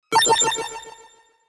耳に心地よく響く8ビットの音。
8bit 爆風音#04懐かしのあの音！ 着信音